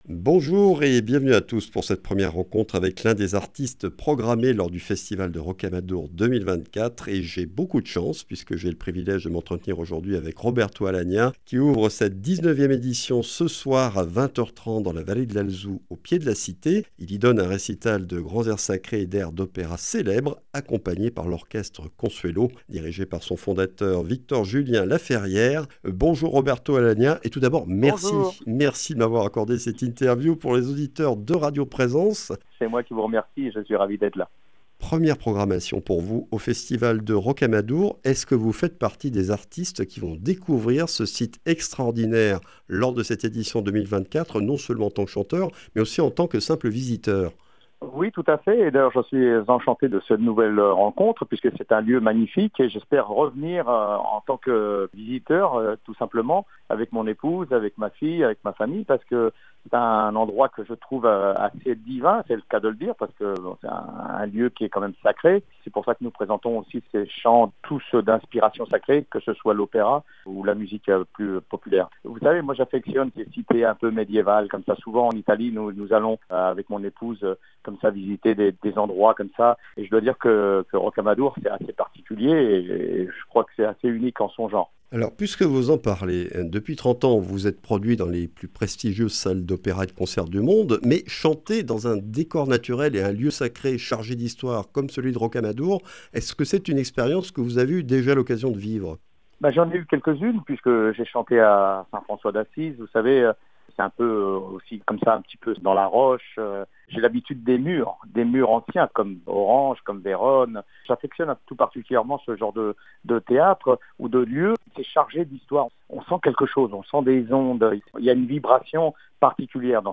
Festival de Rocamadour 2024 : ITW de Roberto Alagna